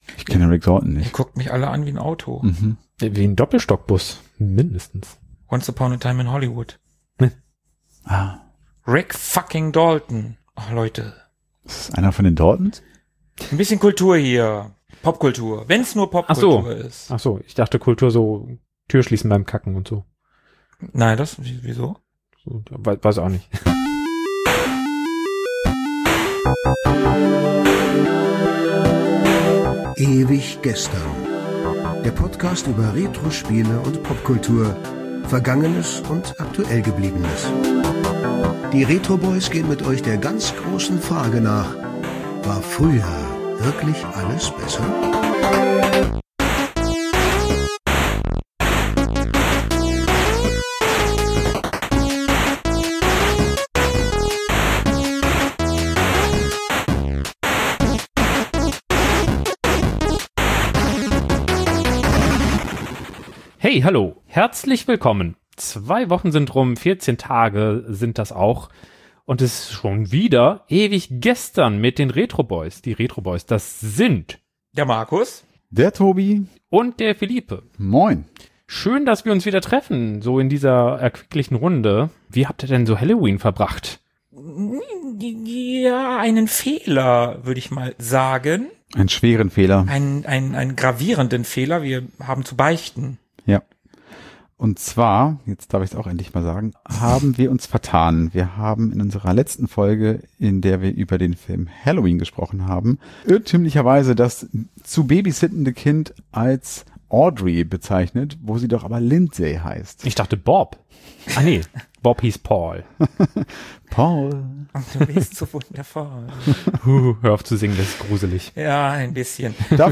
Introsprecher Hans-Georg Panczak (Ja, der.)